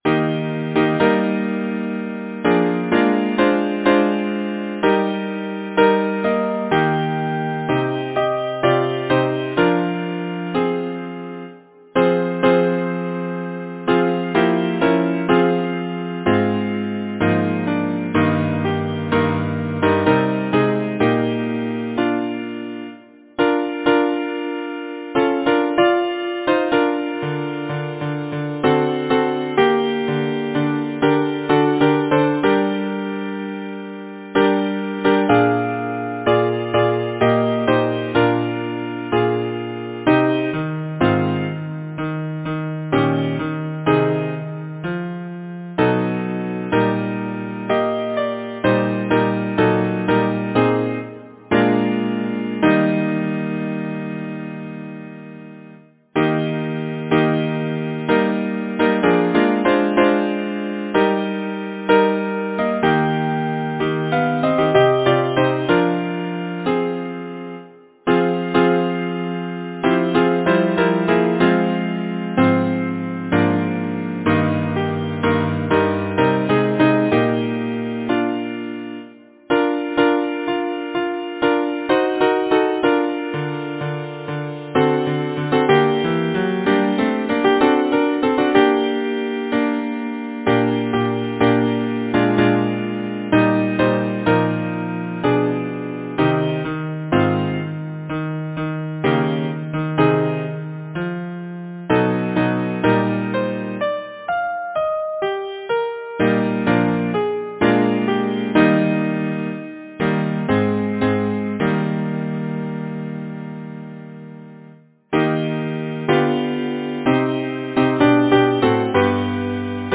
Title: The Shepherd’s Lament Composer: Henry Thomas Smart Lyricists: Johann Wolfgang von Goethe, translated Alfred Baskerville Number of voices: 4vv Voicing: SATB Genre: Secular, Partsong
Language: English Instruments: A cappella